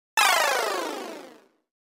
cuttingSound.7e0b2a5e.mp3